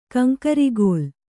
♪ kaŋkarigōl